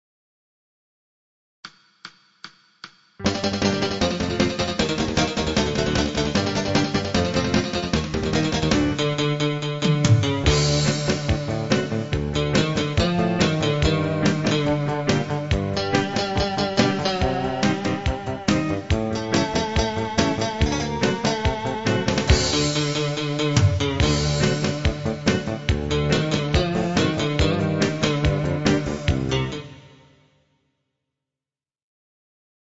INSTRUMENTAL
Pop